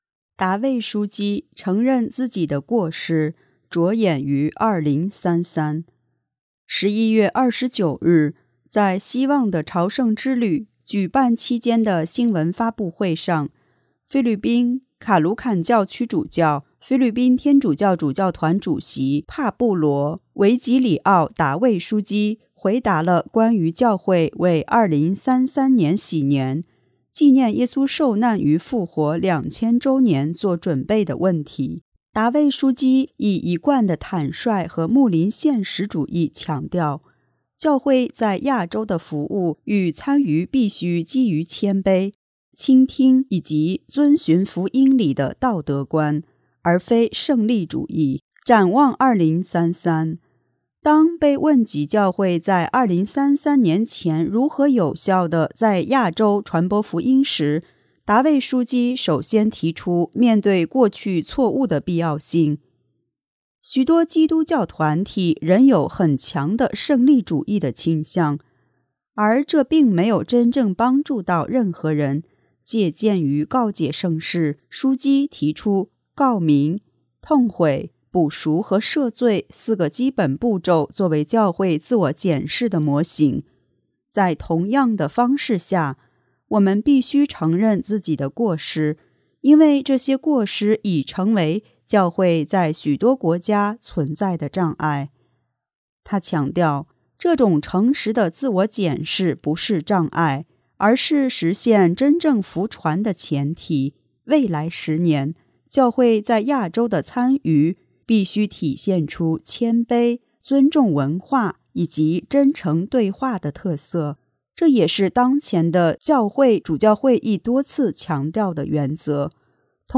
11月29日，在“希望的朝圣之旅”举办期间的新闻发布会上，菲律宾卡卢坎教区主教、菲律宾天主教主教团主席帕布罗·维吉里奥·达味枢机（Pablo Virgilio S. Cardinal David）回答了关于教会为2033年禧年（纪念耶稣受难与复活2000周年）做准备的问题。